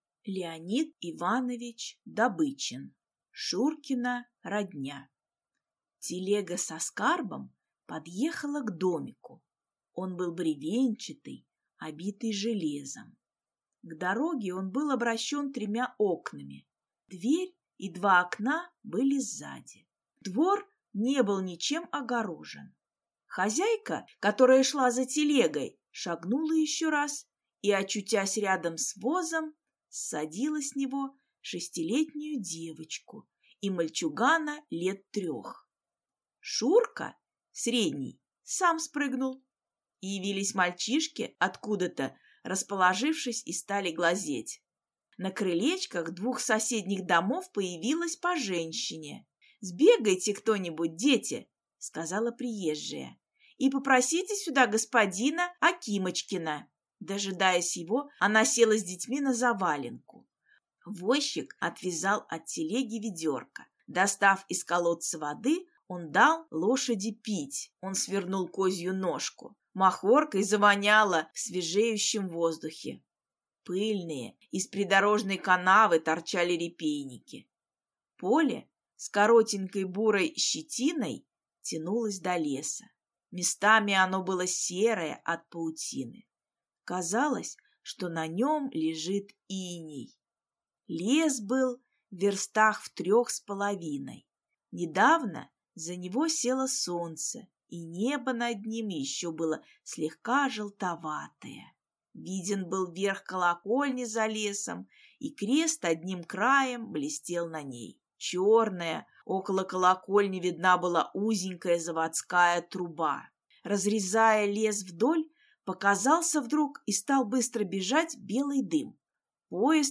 Аудиокнига Шуркина родня | Библиотека аудиокниг